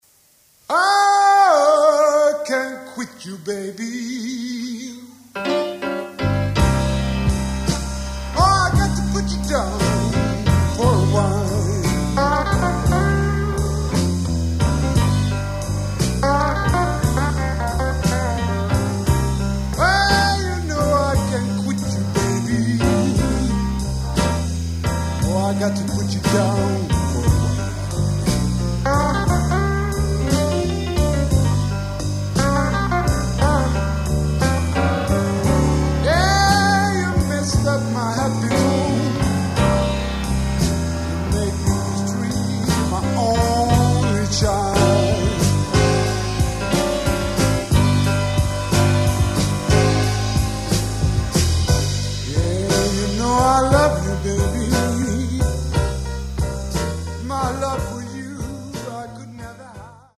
STUDIO, 12-13 september-79:
Sång, munspel
Gitarr 1-7 + 13
Piano
Trummor